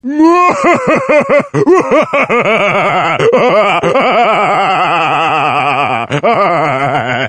Categoría Graciosos